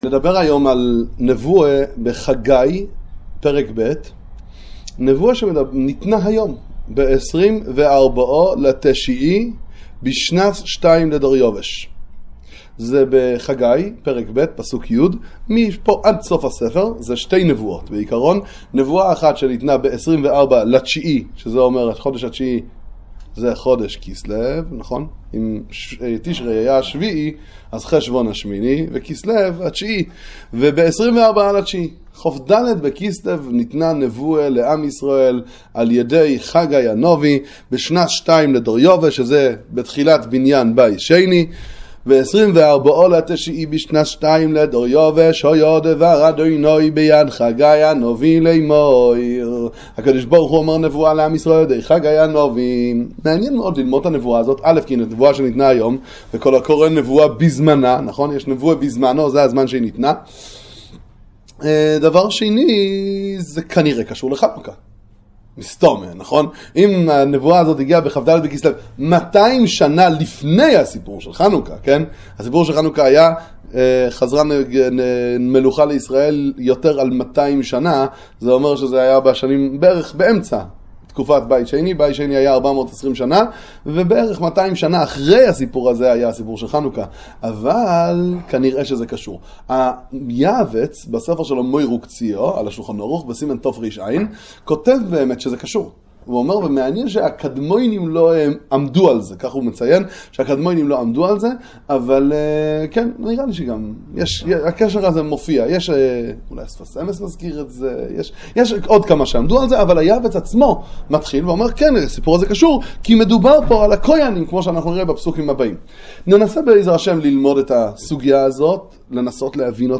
שיעורי תורה לצפיה על חג החנוכה